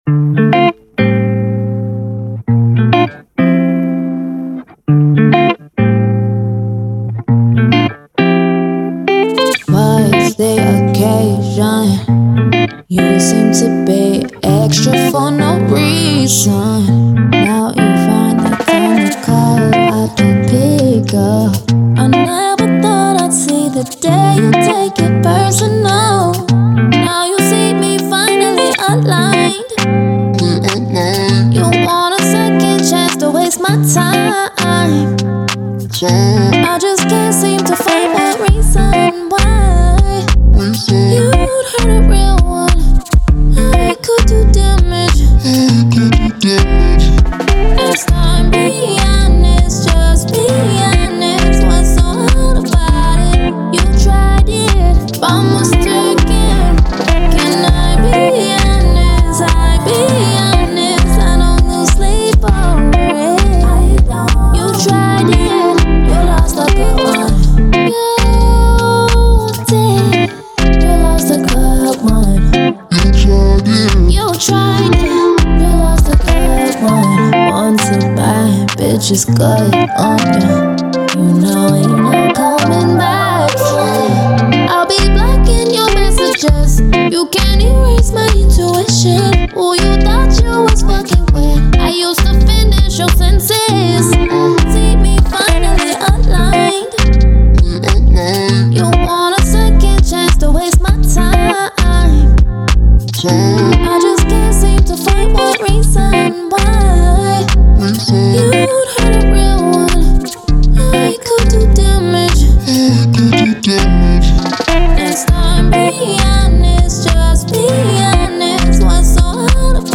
Afrobeat, R&B
D Minor